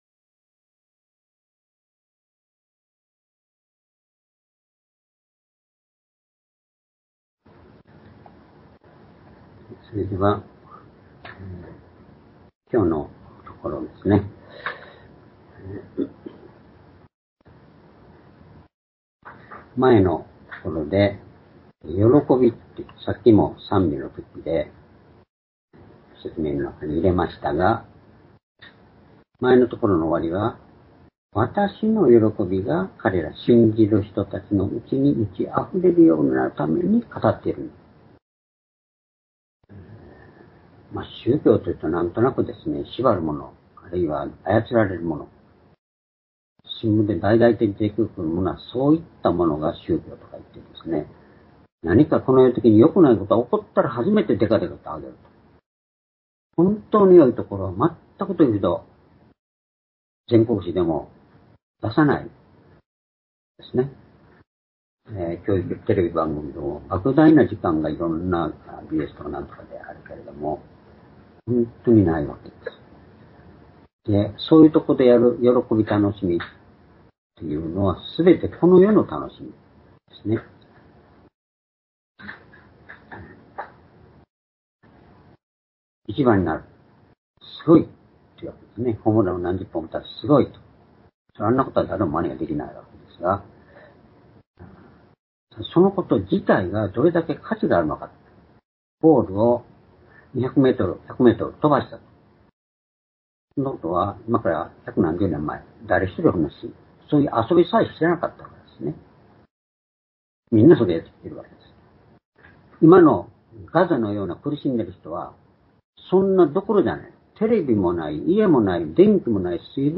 主日礼拝日時 ２０２４年9月8日（主日礼拝） 聖書講話箇所 「聖とされること、悪の力から守られること」 ヨハネ１７章１４節～１７節 ※視聴できない場合は をクリックしてください。